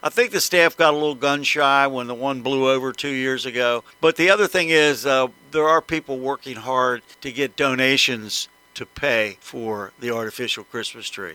City Councilman Rock Cioni explained the city’s thinking on the matter…